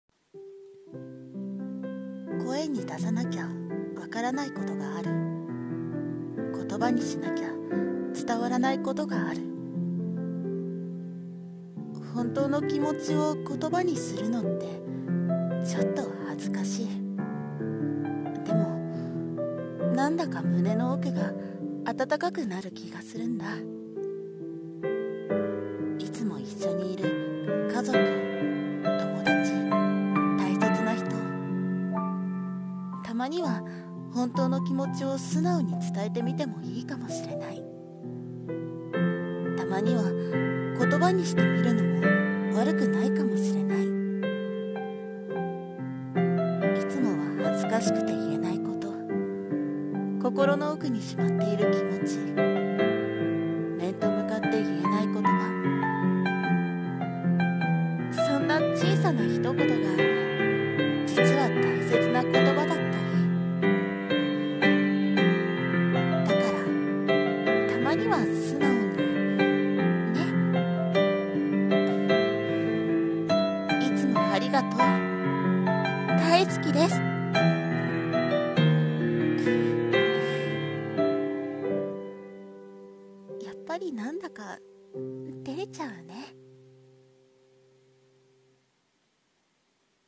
[声劇･朗読]たまには素直に[台本